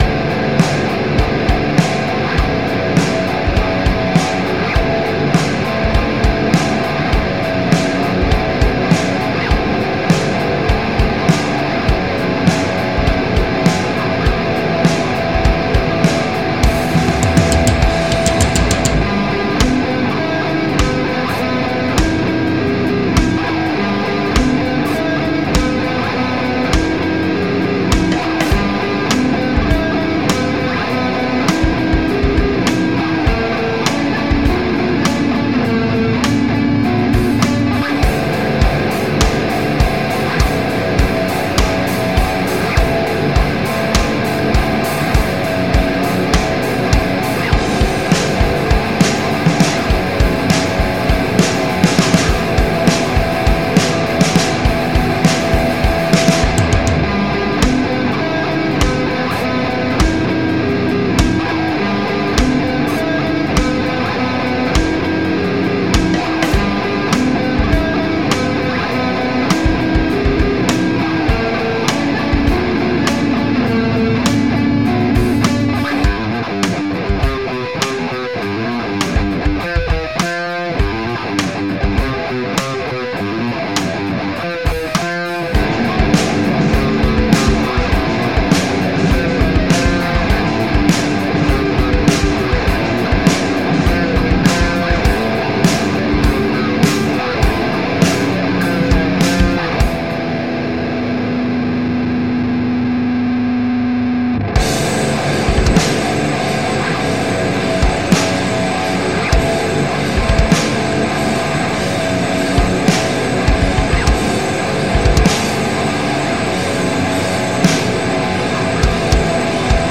Challenge: SAWTOOTH MELODY
Crazy lyrics, but I like the vibe.